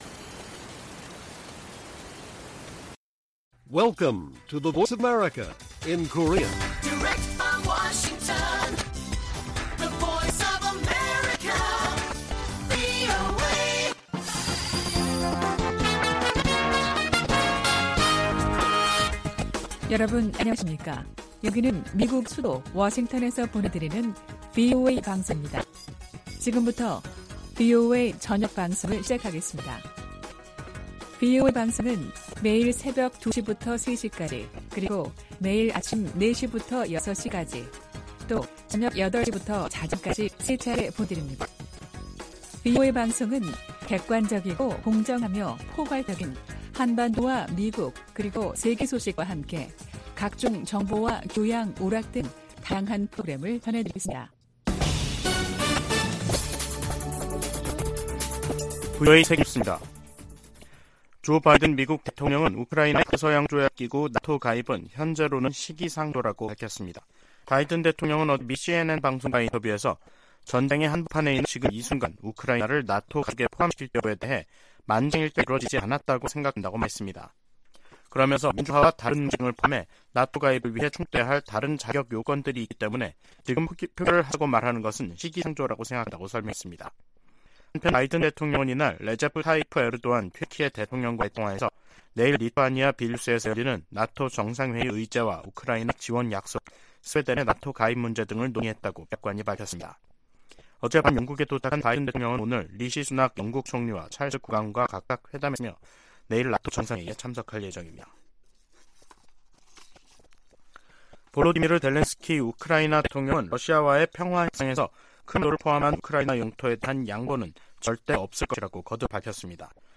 VOA 한국어 간판 뉴스 프로그램 '뉴스 투데이', 2023년 7월 10일 1부 방송입니다. 미국과 한국이 오는 18일 서울에서 핵협의그룹 출범회의를 엽니다. 백악관은 미한 핵협의그룹(NCG) 회의의 중요성을 강조하며 고위급 인사가 참여할 것이라고 밝혔습니다. 북한 당국이 일본 후쿠시마 오염수 방출 계획을 비난하는데 대해 미국 전문가들은 북한의 핵시설 오염수 관리가 더 큰 문제라고 지적했습니다.